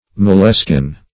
moleskin \mole"skin`\ (m[=o]l"sk[i^]n), n.